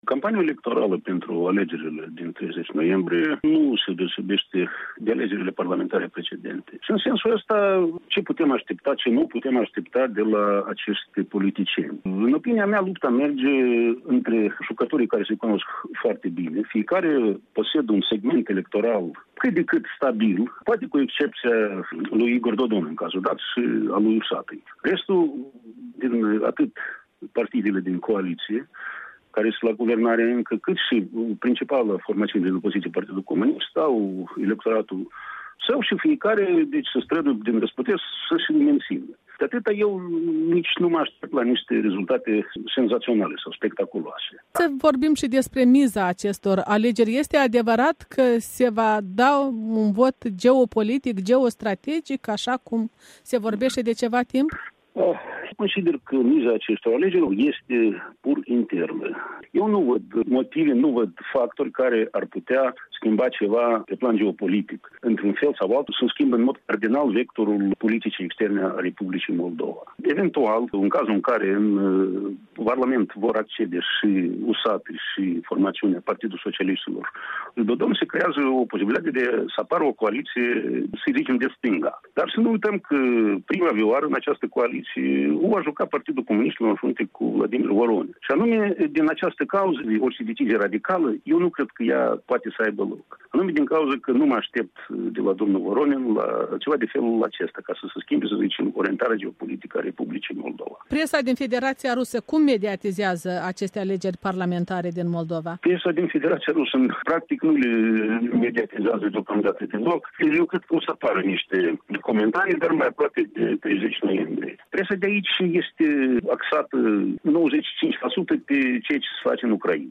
Prin telefon de la Moscova